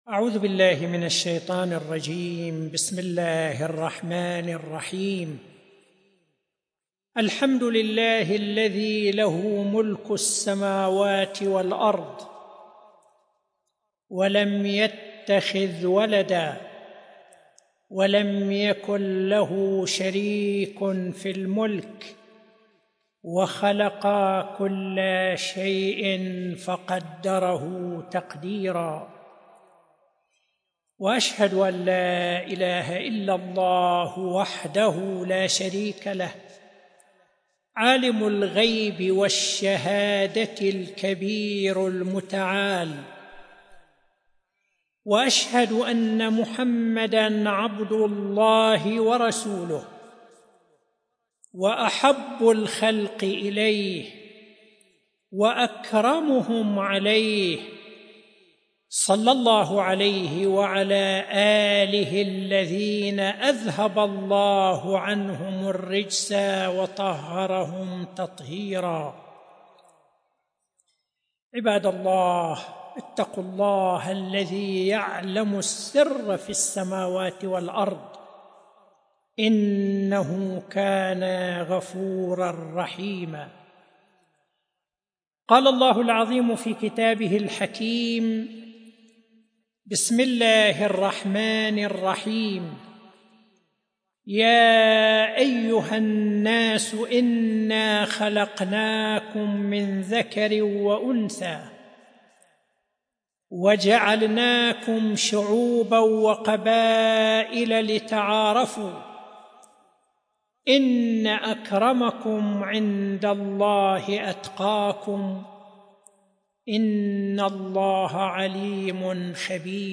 ملف صوتی تبادل الخبرات والتجارب بين المجتمعات بصوت الشيخ حسن الصفار